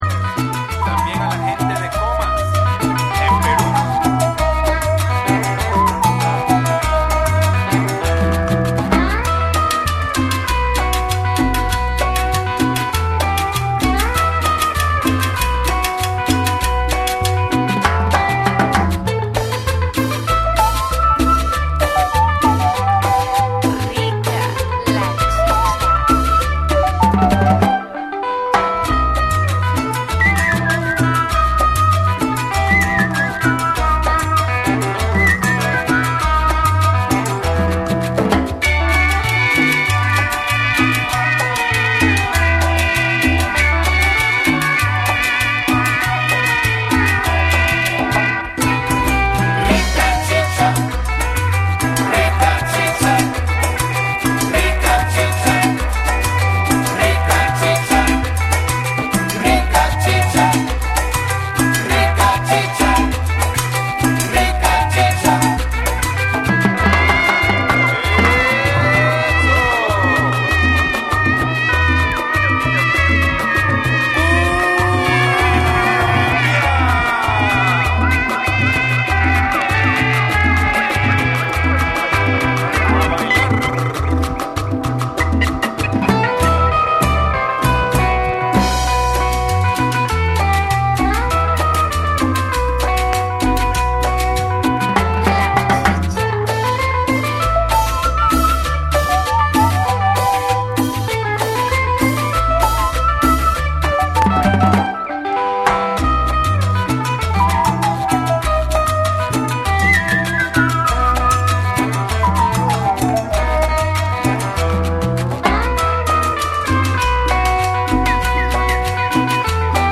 クンビアにサイケデリックでサーフロックに通じるギターのメロディーが融合したジャンル